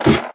sounds / step / ladder5.ogg
ladder5.ogg